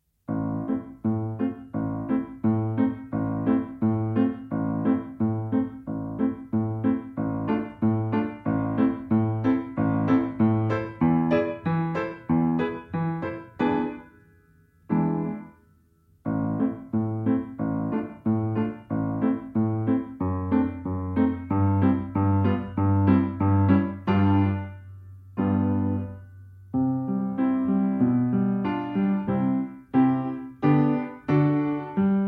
Nagranie bez metronomu, uwzględnia rubata.
Allegro moderato: 88 bmp
Nagranie dokonane na pianinie Yamaha P2, strój 440Hz